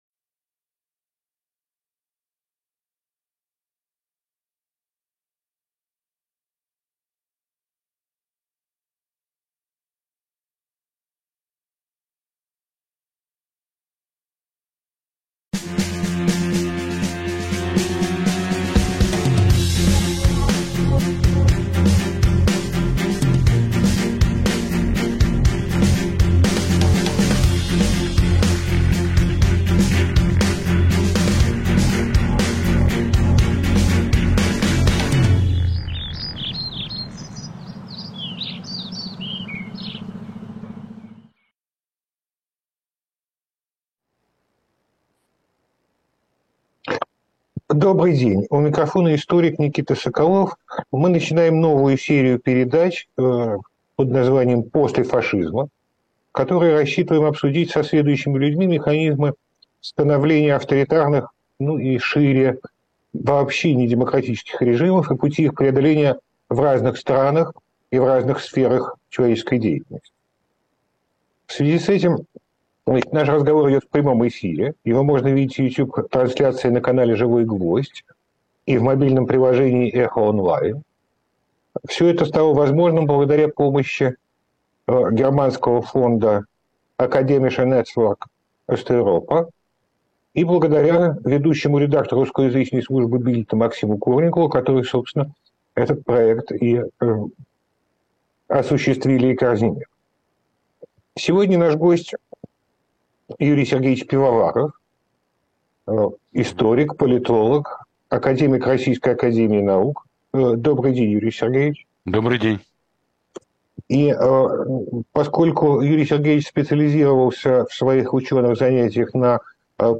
Наш разговор идет в прямом эфире.